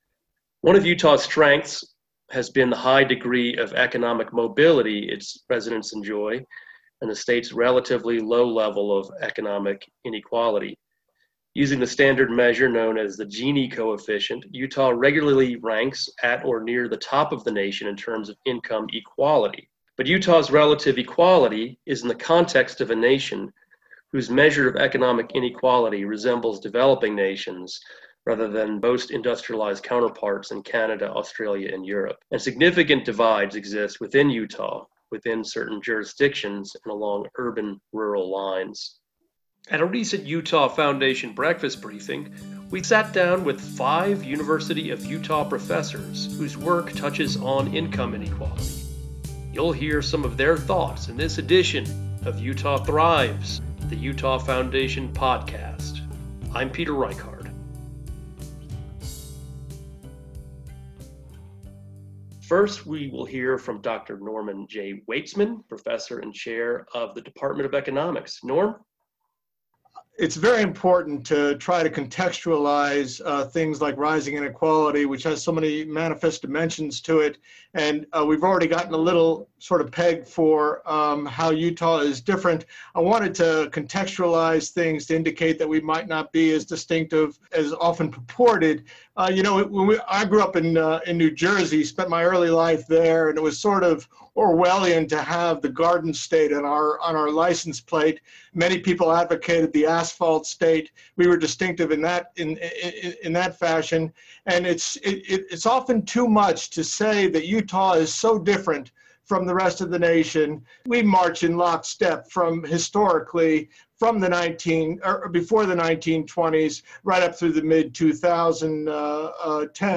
This episode of Utah Thrives draws from our recent Breakfast Briefing on income inequality in Utah. The discussion with University of Utah professors looks especially at social mobility, health and education challenges in Salt Lake County.